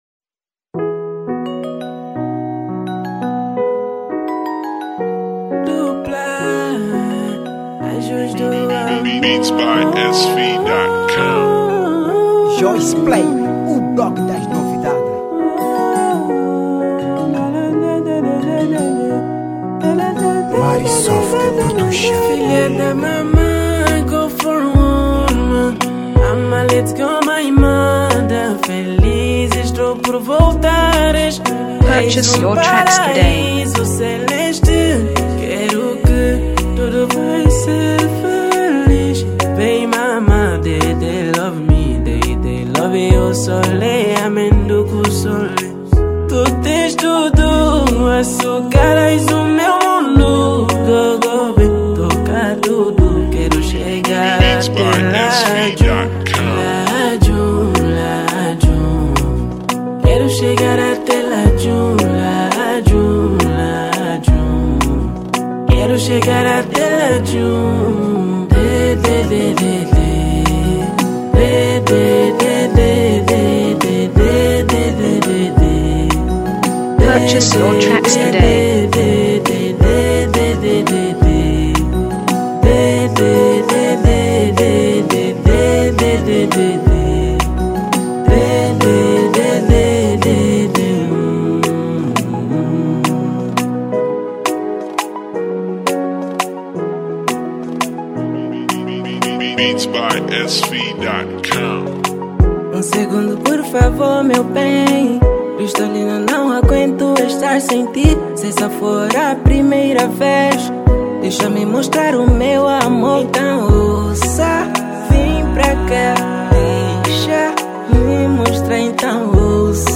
Género: R&B